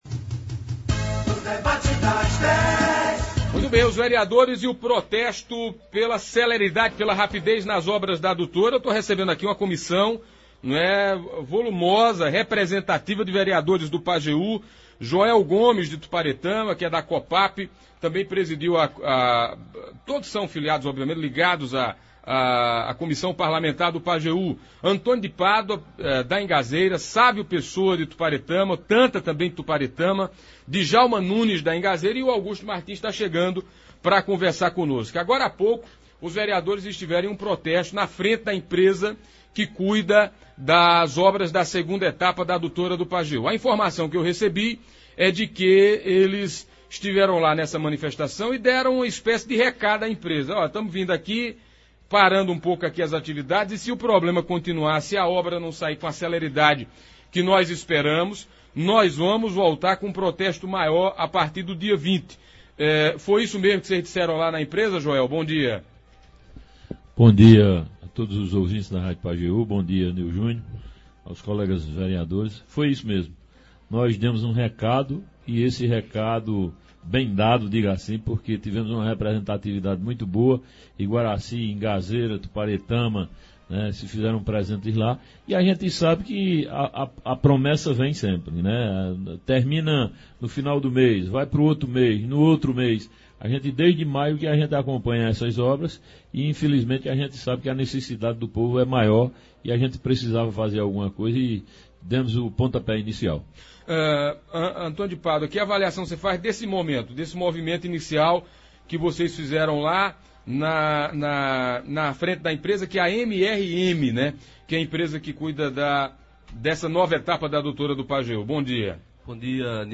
A comissão esteve nos estúdios da Pajeú esclarecendo à população os motivos que os levaram a realizar o protesto e quais serão as próximas medidas adotas por eles.
Ouça na íntegra como foi a entrevista com os vereadores: